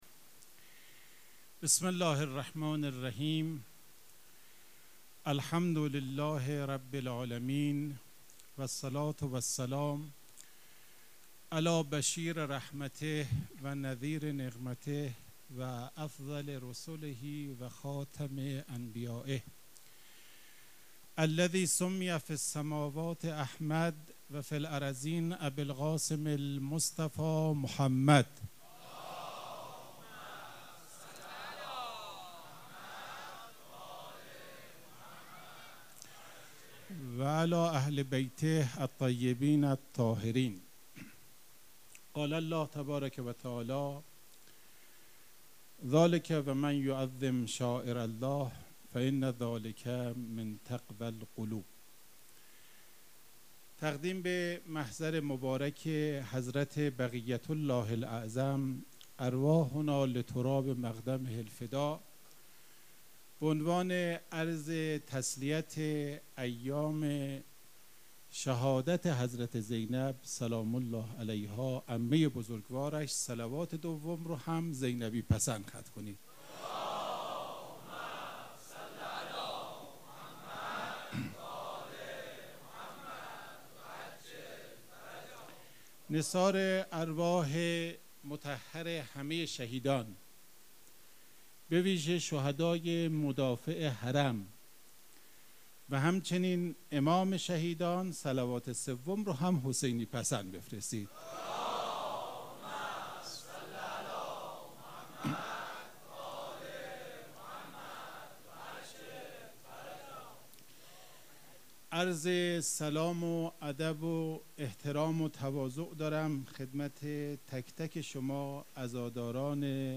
سخنرانی
مراسم شهادت حضرت زینب کبری(سلام الله عليها) چهارشنبه ٢٣فروردین١٣٩٦ مجتمع فرهنگی مذهبی ریحانة الحسین(س)
سخنرانی.mp3